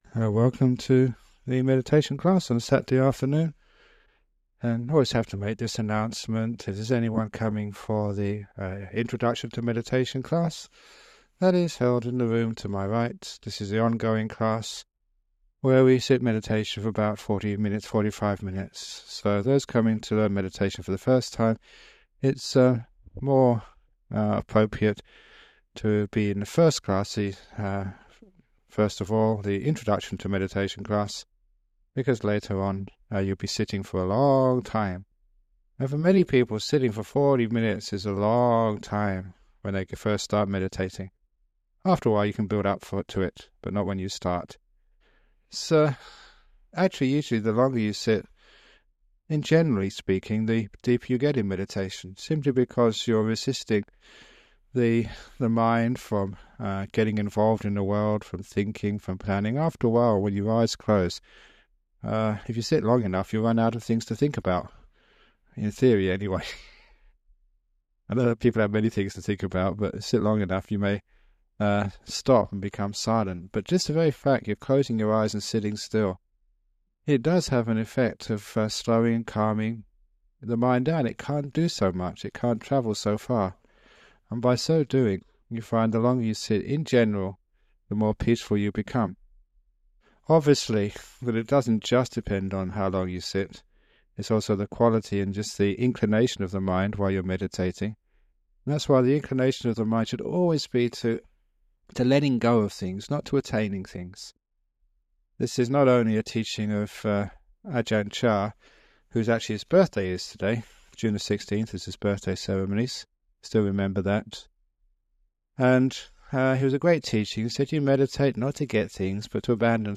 It includes a talk about some aspect of meditation followed by a 45 minute guided meditation. This guided meditation has been remastered and published by the Everyday Dhamma Network , and will be of interest to people who have started meditation but are seeking guidance to take it deeper.